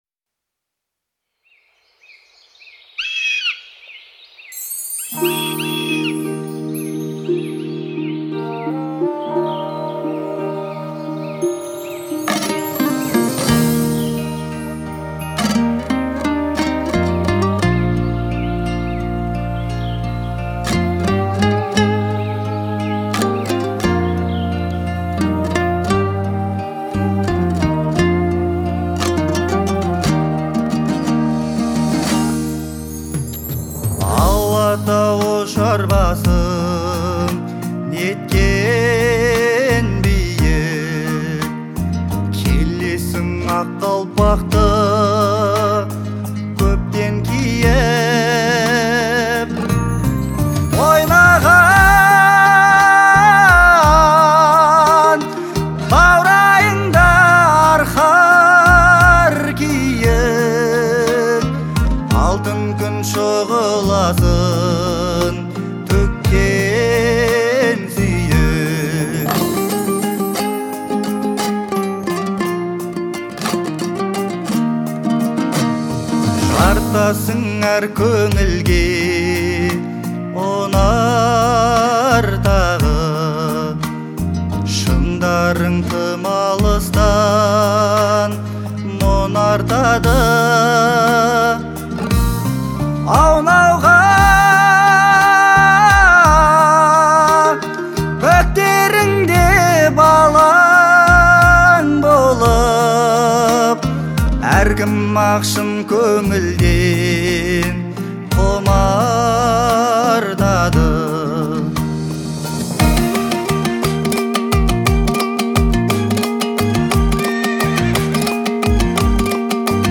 относится к жанру казахской поп-музыки